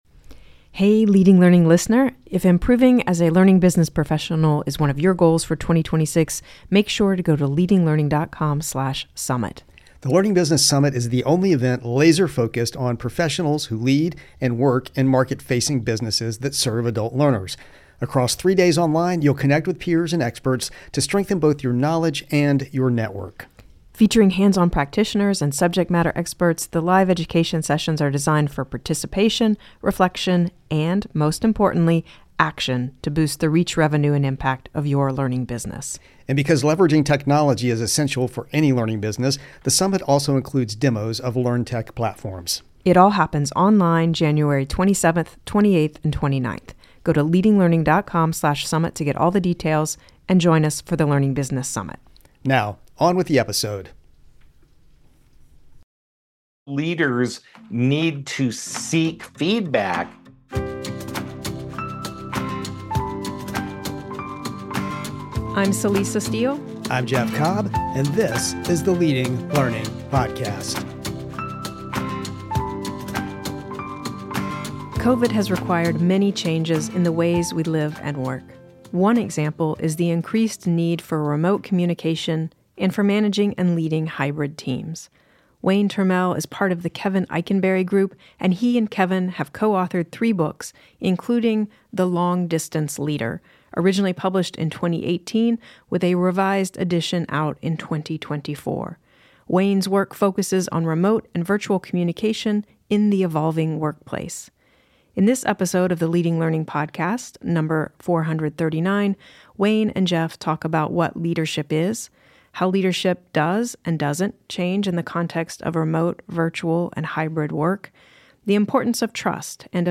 Leading Learning Podcast interviewee